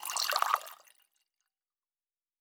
pgs/Assets/Audio/Fantasy Interface Sounds/Food Drink 05.wav at master
Food Drink 05.wav